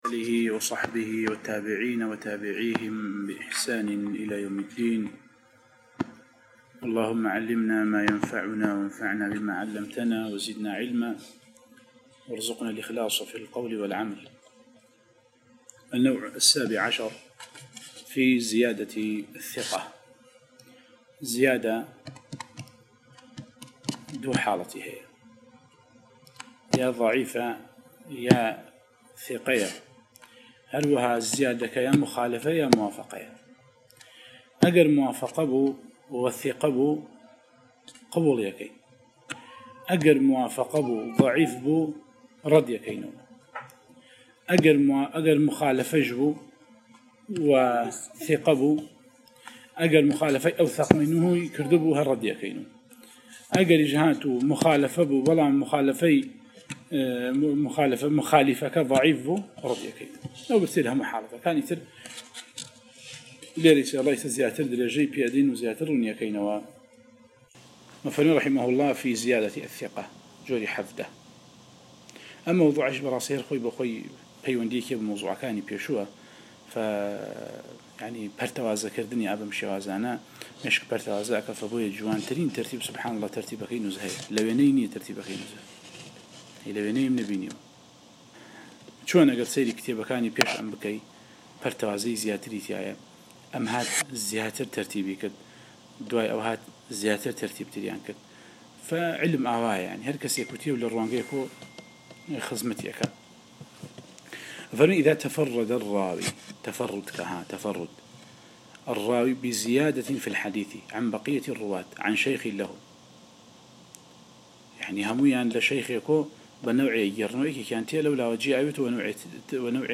القراءة والتعليق على مواضع من الباعث الحثيث ـ 9